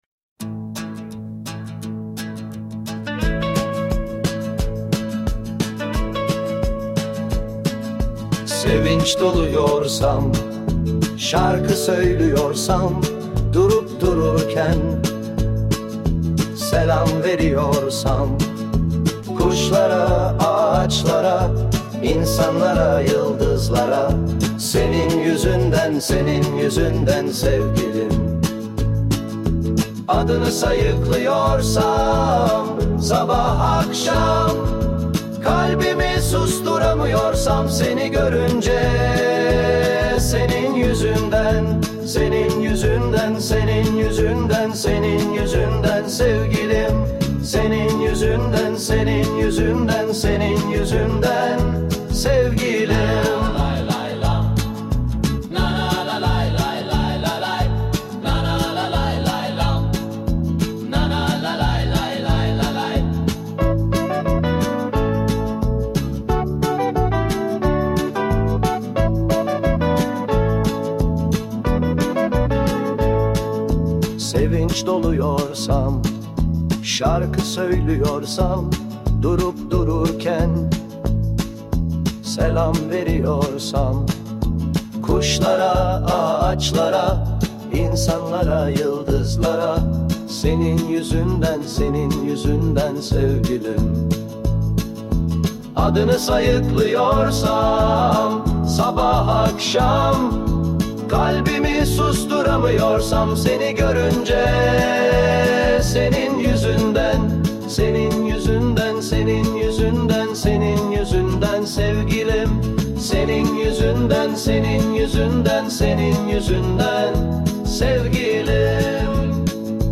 Tür : Pop, Rock